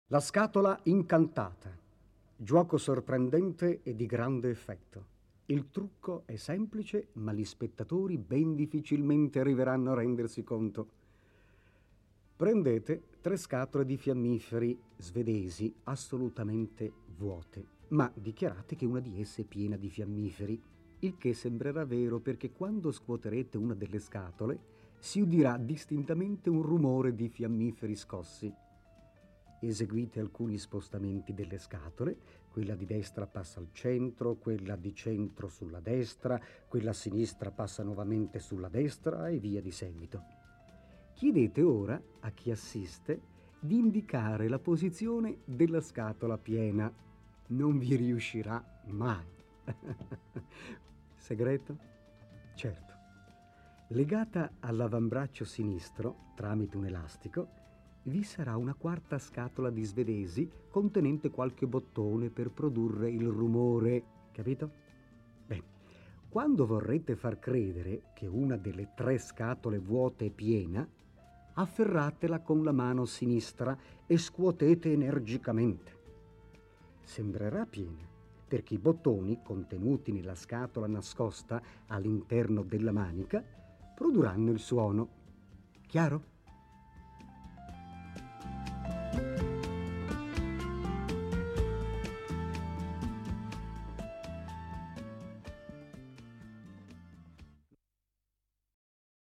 Le ultime tre tracce erano altrettanti giochi di prestigio spiegati da Silvan su un sottofondo musicale.